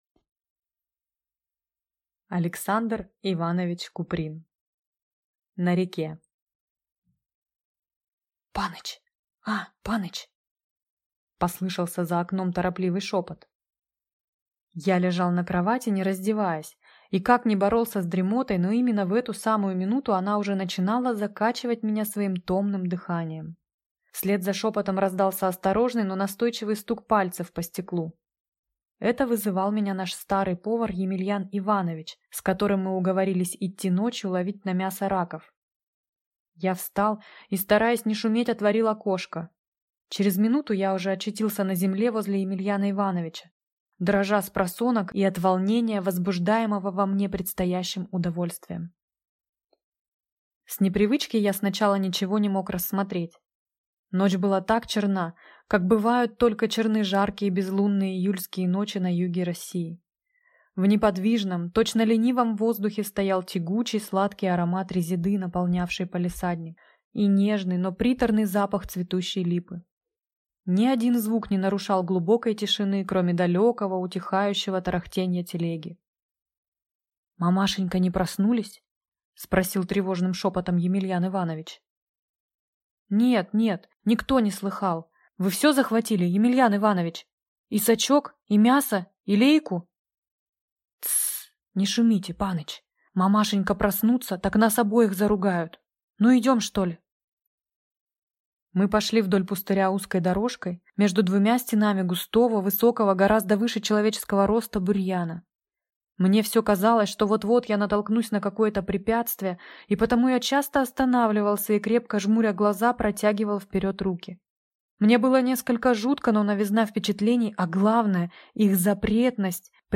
Аудиокнига На реке | Библиотека аудиокниг